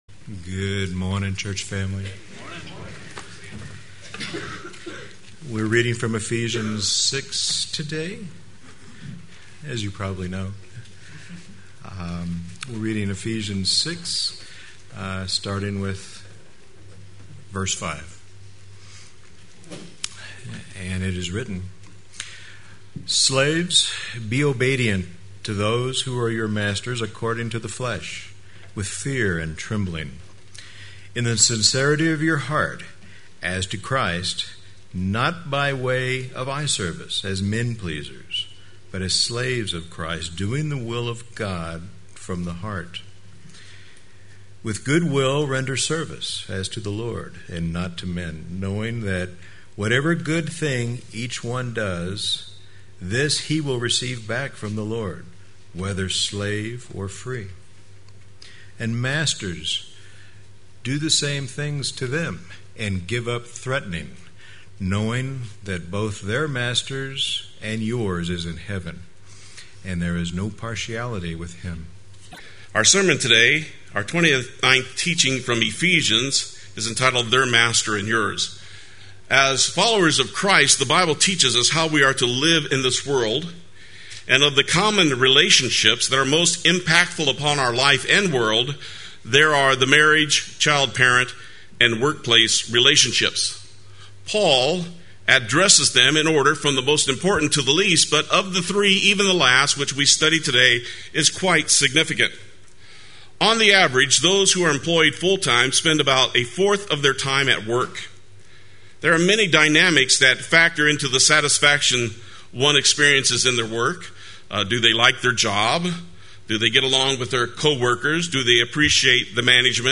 Play Sermon Get HCF Teaching Automatically.
“Their Master and Yours” Sunday Worship